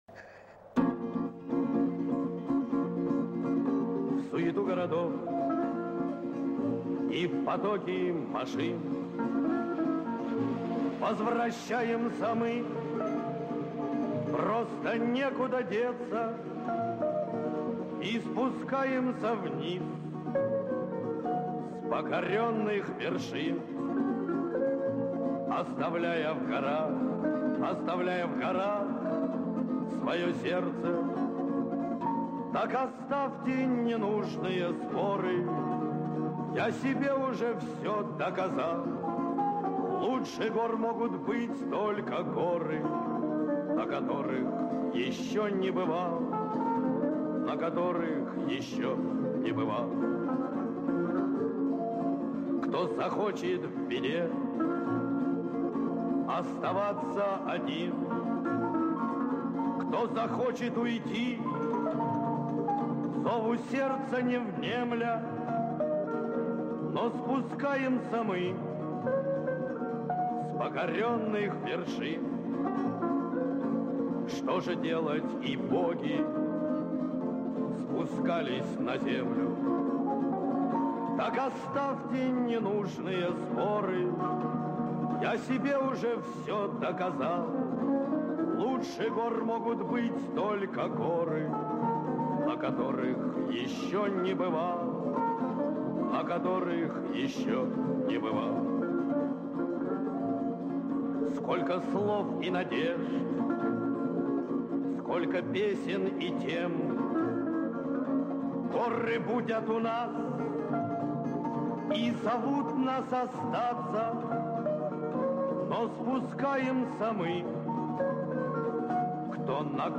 с оркестром